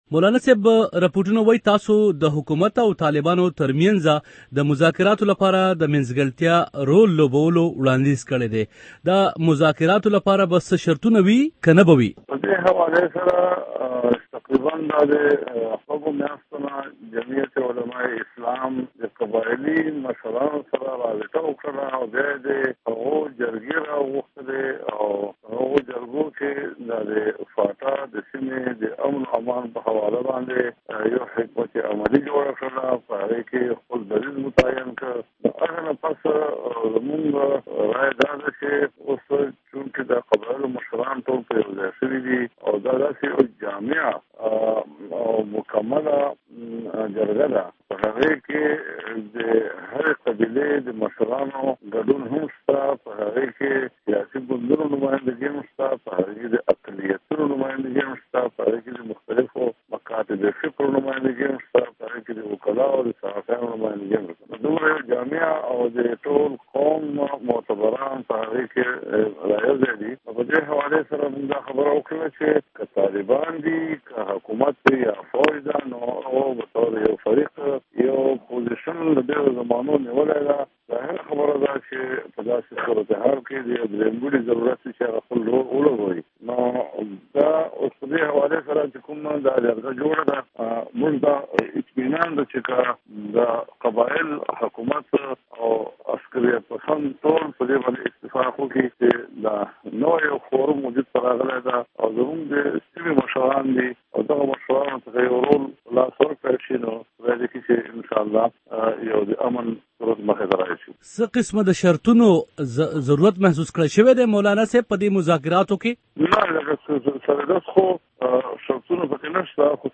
Molana Fazlue Rehman Interview